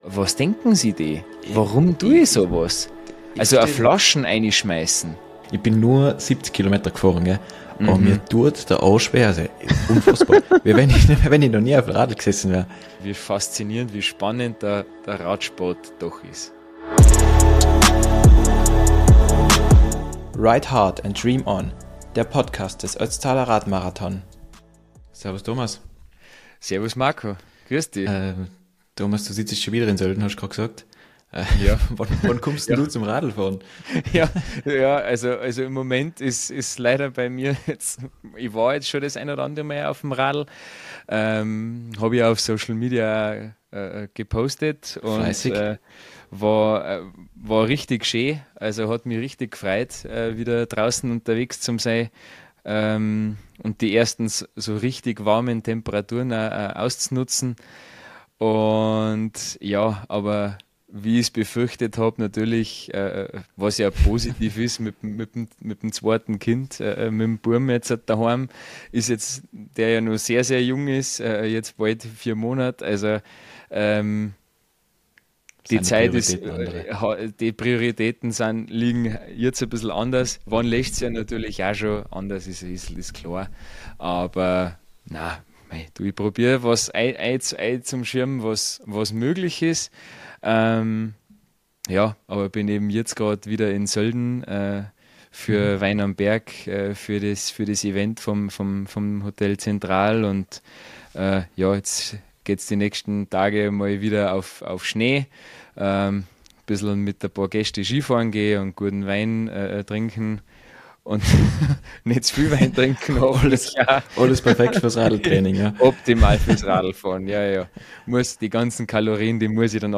Tandem Talk